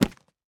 Minecraft Version Minecraft Version snapshot Latest Release | Latest Snapshot snapshot / assets / minecraft / sounds / block / chiseled_bookshelf / pickup3.ogg Compare With Compare With Latest Release | Latest Snapshot
pickup3.ogg